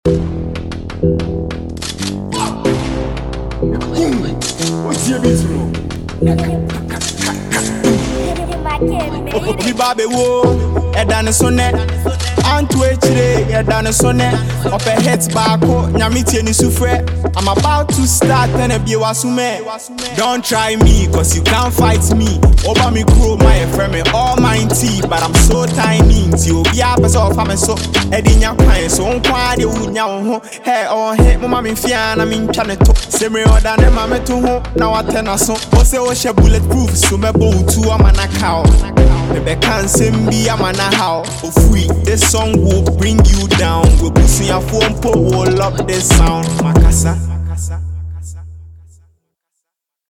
freestyle